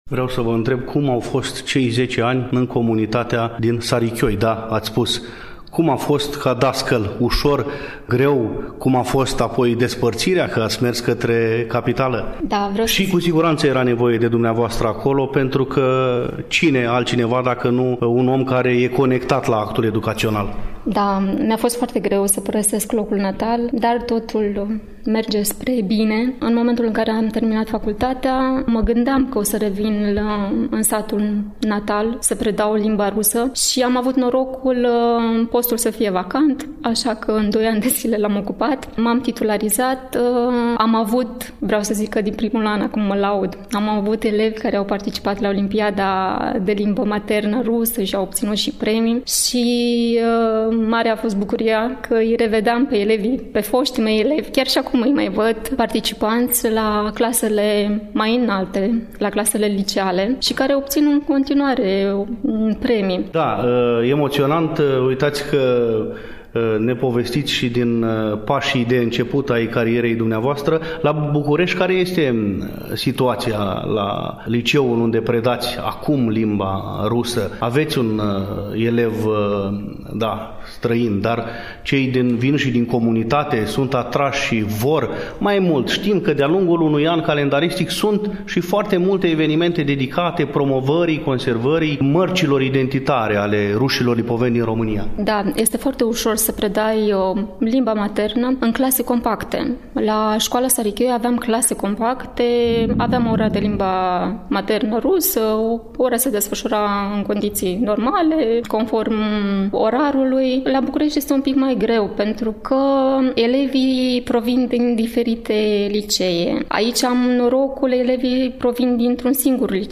Primul interlocutor al emisiunii este doamna profesoară de limba rusă